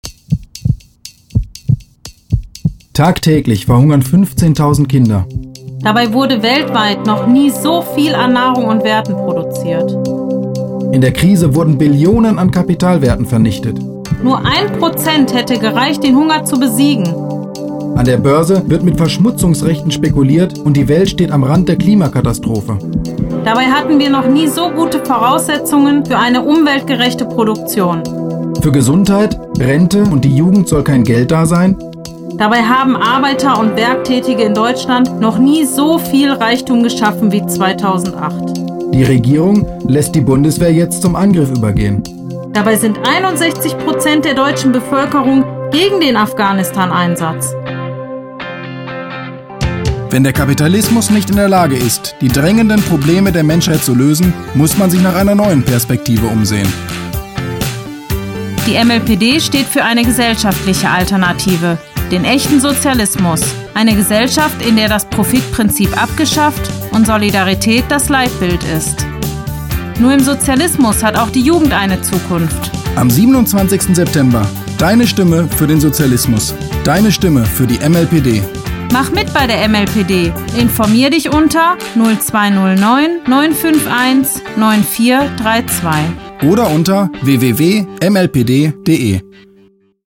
MLPD Radiospot Bundestagswahl 2009